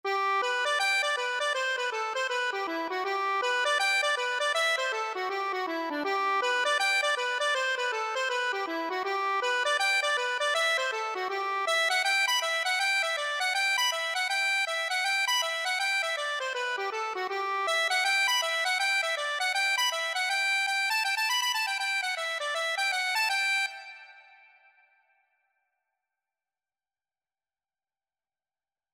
4/4 (View more 4/4 Music)
Accordion  (View more Easy Accordion Music)